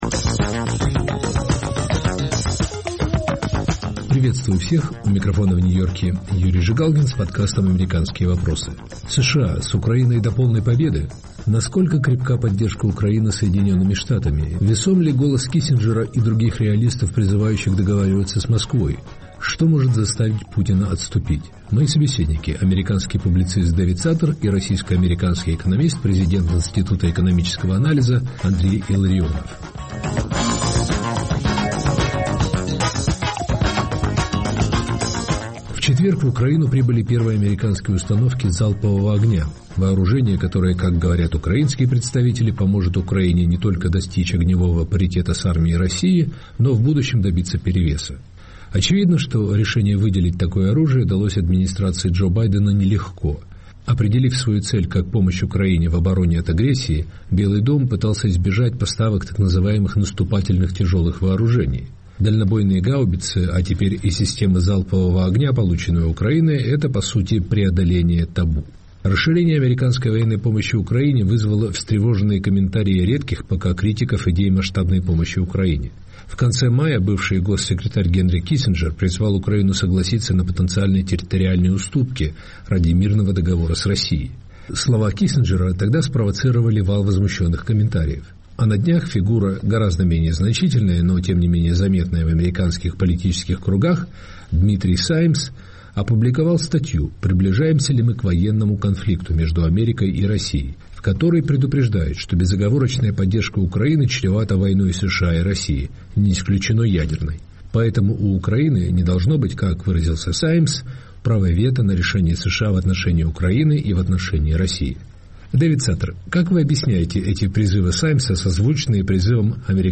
Мои собеседники – американский публицист Дэвид Саттер и президент Института экономического анализа Андрей Илларионов.